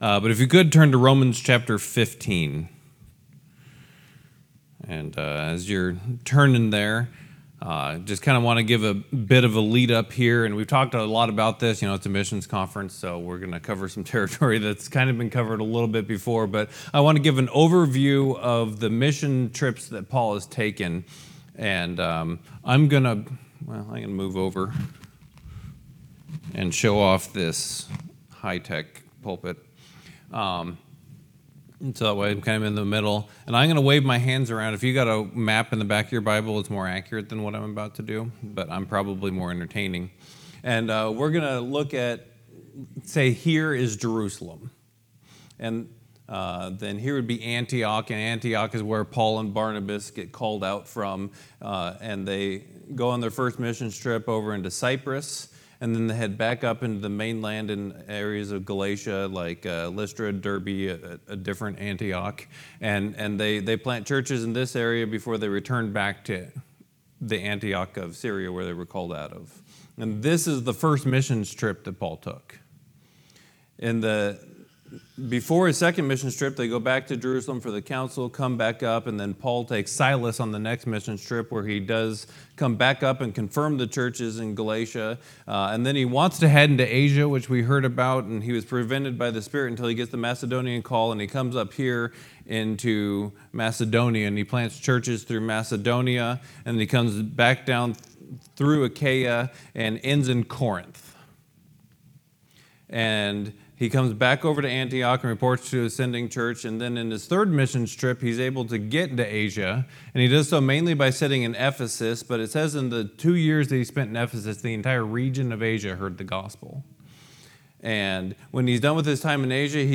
Adult Sunday School: Mission Conference 2025 &middot